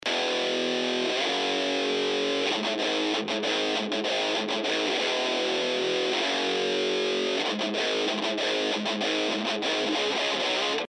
このフェード処理されたものをそれぞれ重ねてつなぎ目を自然にしてるんですね。
先程まであった7秒付近のプツッという音が見事になくなってますね！！！